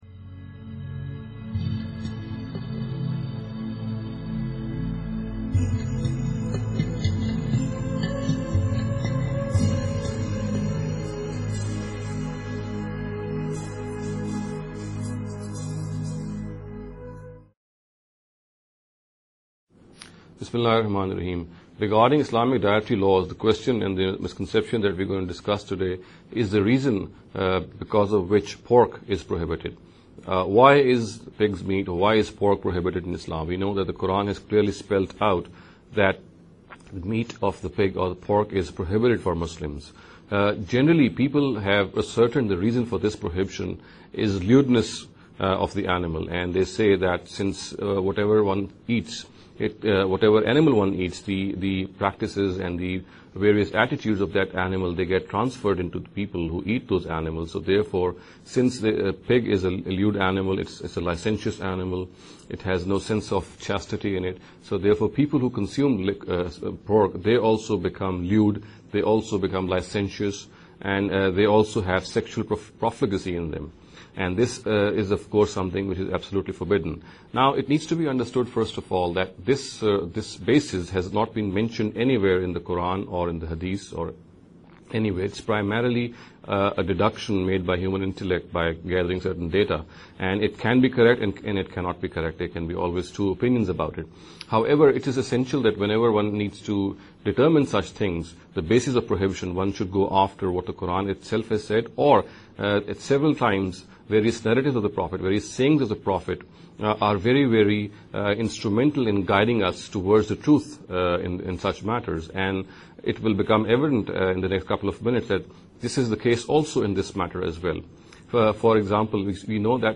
This lecture series will deal with some misconception regarding The Dietary Directives of Islam.